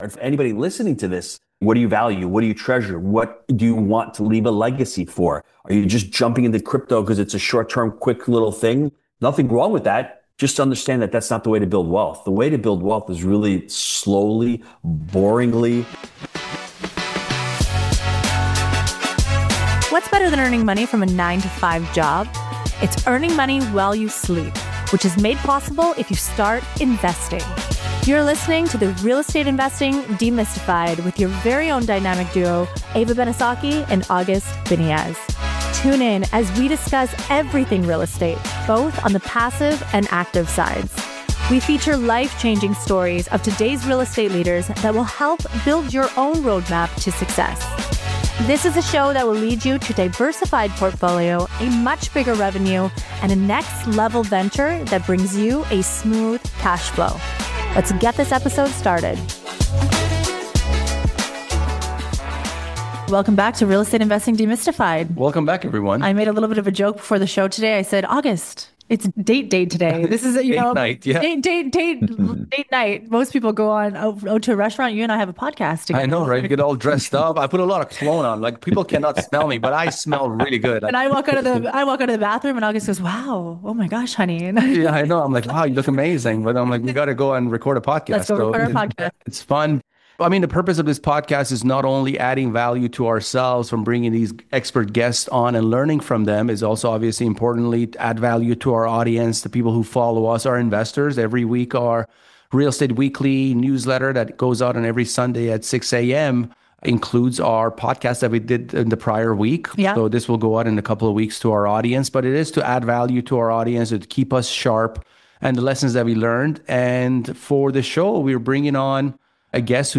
a conversation that goes far beyond market timing and tactics.